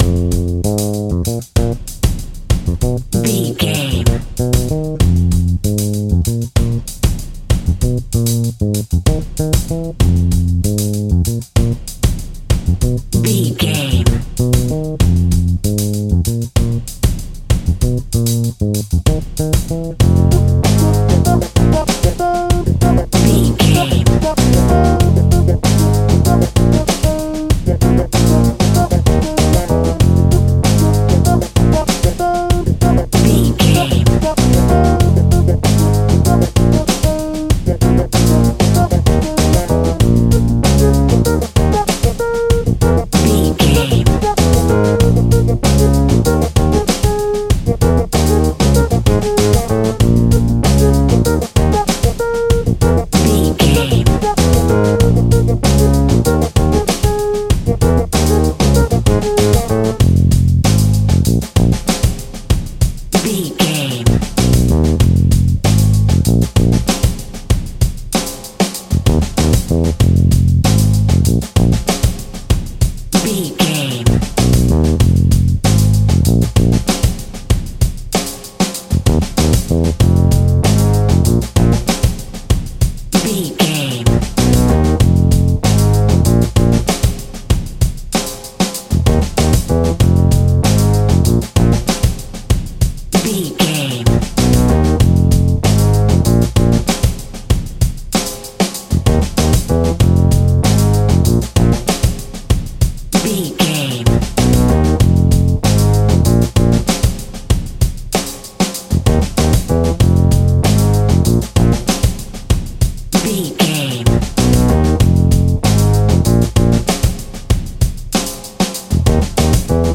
Aeolian/Minor
groovy
lively
electric guitar
electric organ
drums
bass guitar
saxophone
percussion